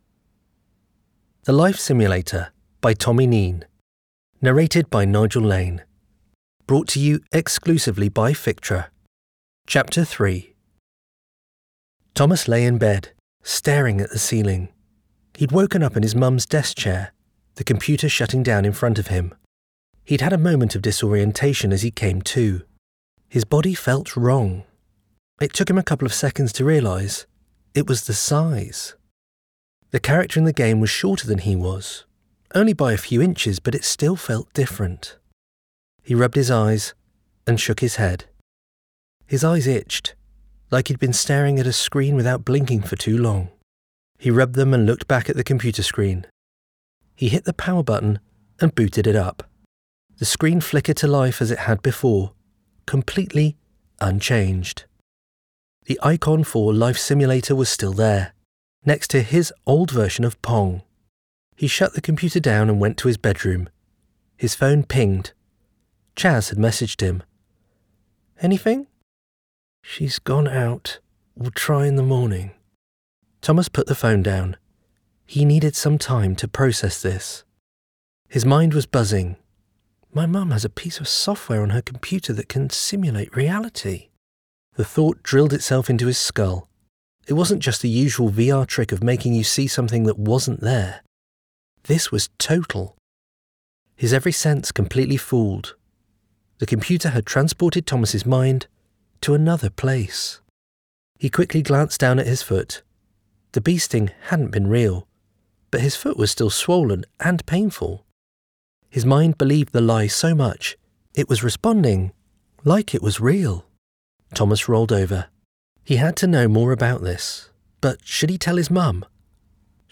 Audio Book Voice Over Narrators
Adult (30-50) | Older Sound (50+)